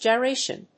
音節gy・ra・tion 発音記号・読み方
/dʒɑɪréɪʃən(米国英語), dʒaɪˈreɪʃʌn(英国英語)/